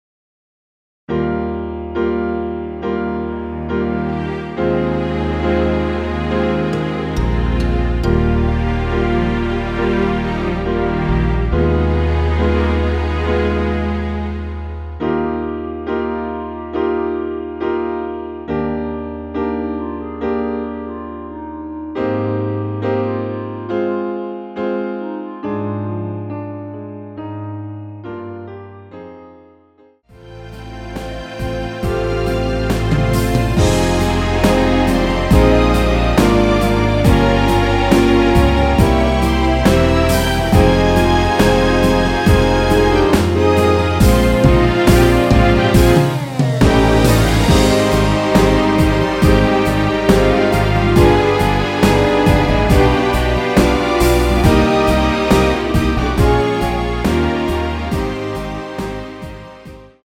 원키에서(-1)내린 멜로디 포함된 MR입니다.
Eb
앞부분30초, 뒷부분30초씩 편집해서 올려 드리고 있습니다.
중간에 음이 끈어지고 다시 나오는 이유는